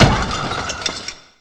car_crash.ogg